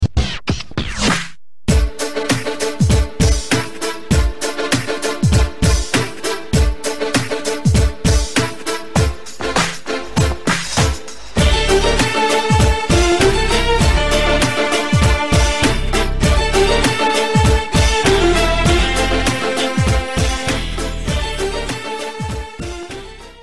громкие
Хип-хоп
без слов
скрипка
инструментальные
качающие